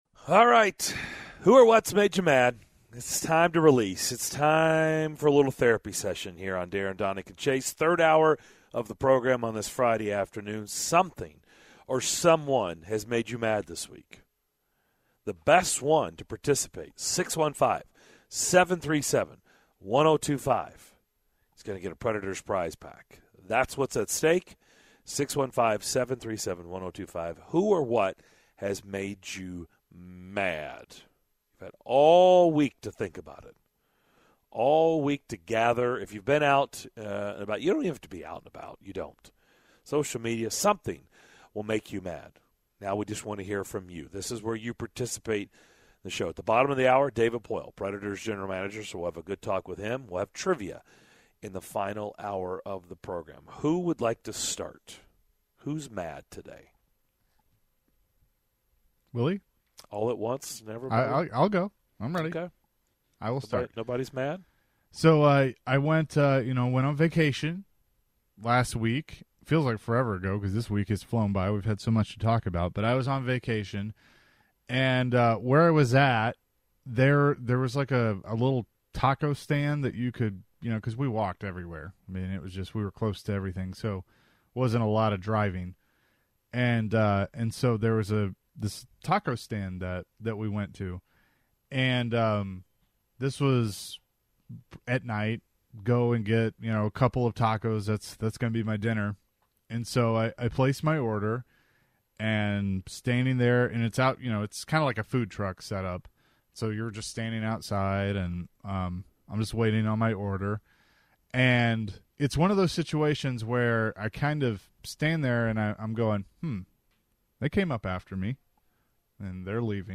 In the third hour of Friday's DDC: the show's callers chime in with that made them mad in this week's You Just Made The List! Preds GM David Poile also joined the show after his team's 2-0 win over the Stars in their exhibition.